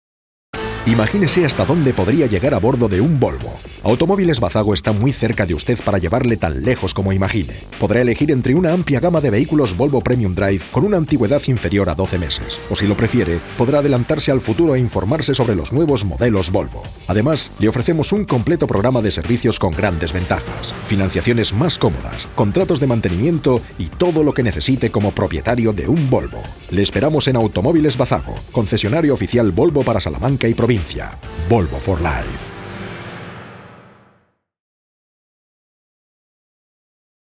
voz española versatil , calida ,experiencia en narracion , e-learning , publicidad y doblaje
kastilisch
Sprechprobe: Werbung (Muttersprache):